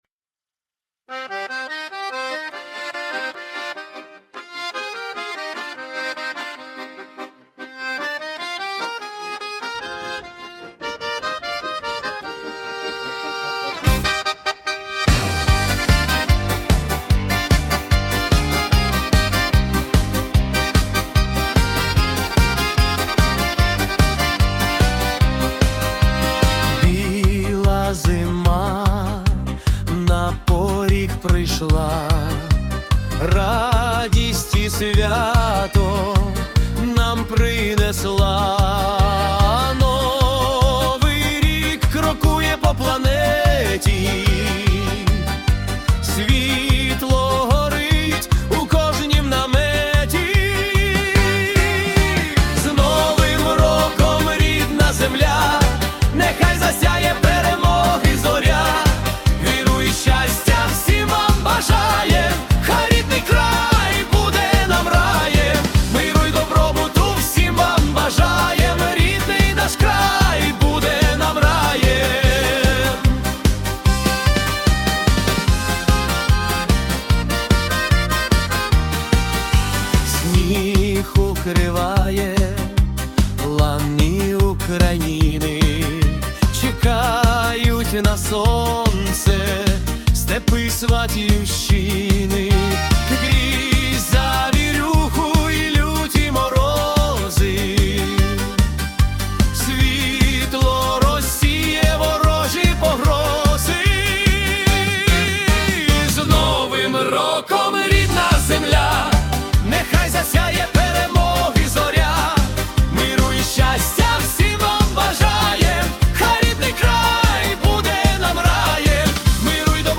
🎵 Жанр: Українська полька